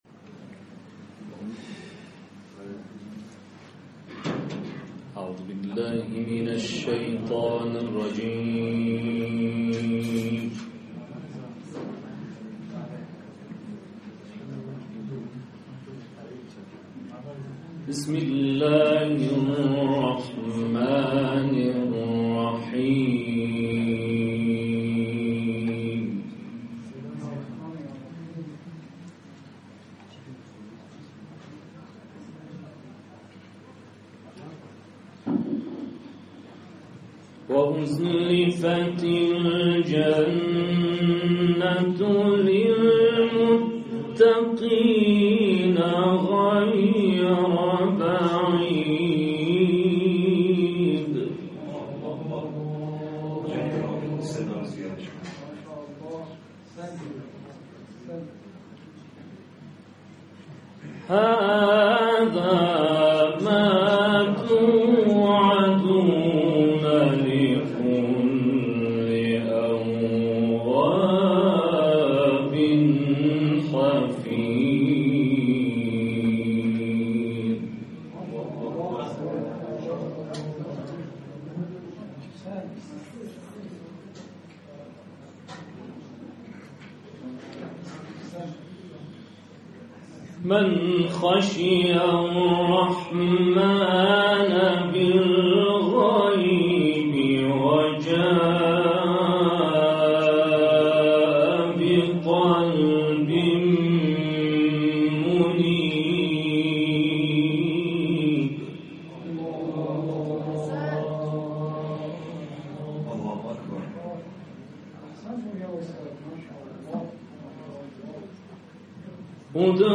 تلاوت کوتاه
در حسینیه الزهرا(س) اجرا شده است